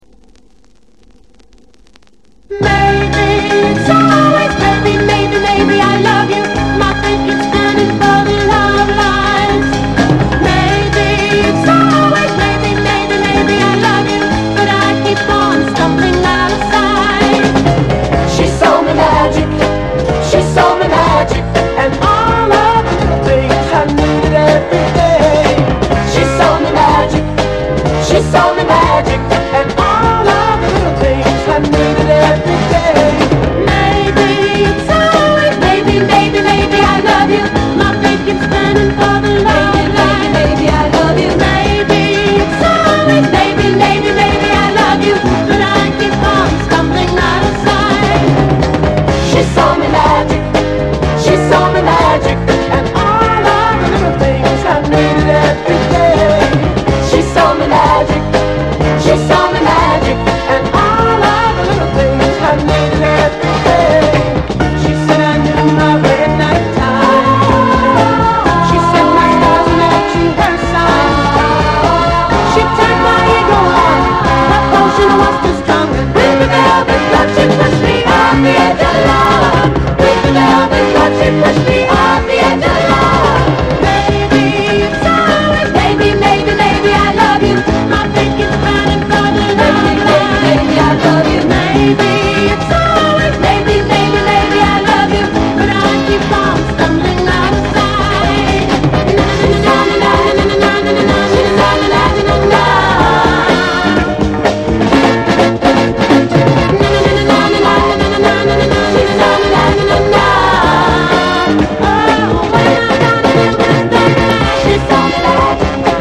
グルーヴィー・ソフトロック〜バブルガムポップとしても秀逸！